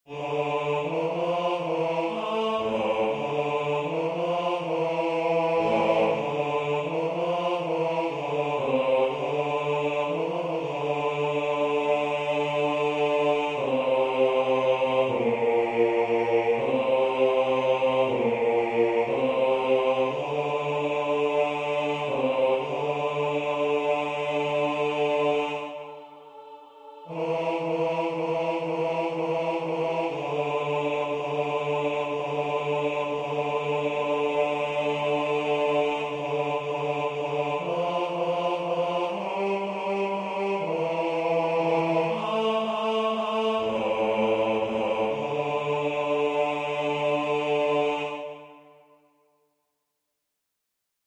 version voix synth.